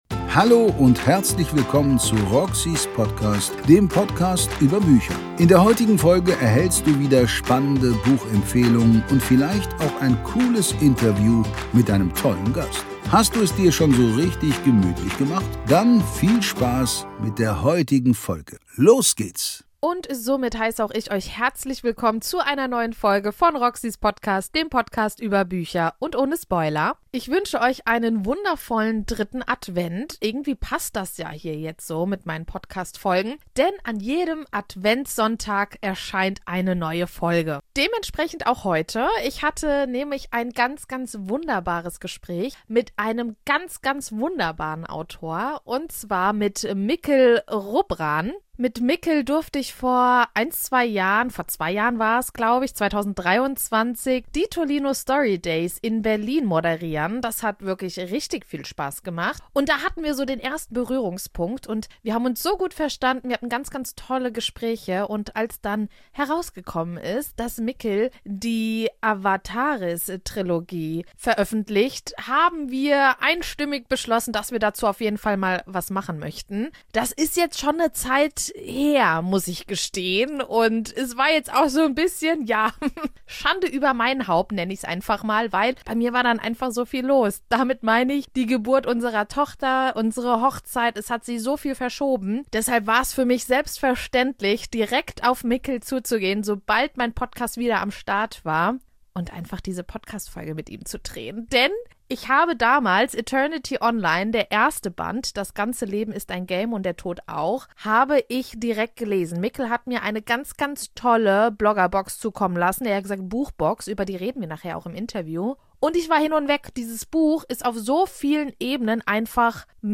Folge #302 Eternity online inkl. Interview